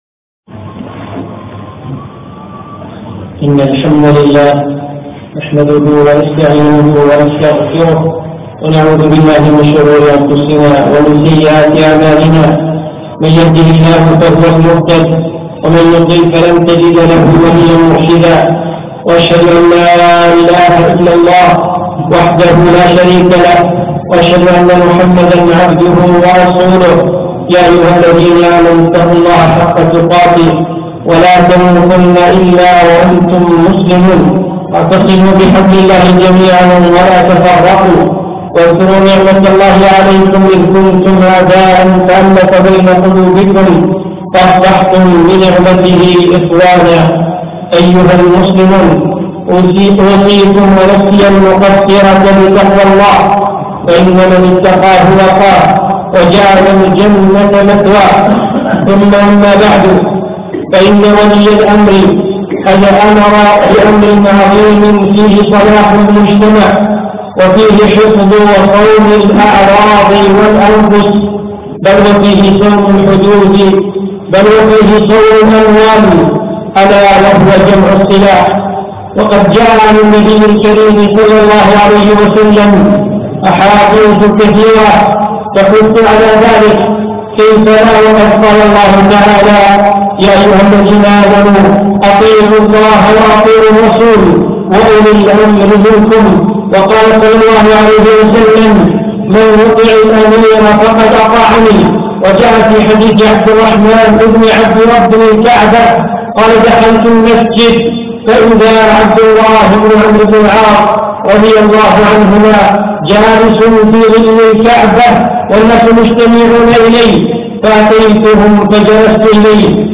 خطبة بتاريخ 6 3 2015 في مسجد أبو موسى الأشعري منطفة الفحيحيل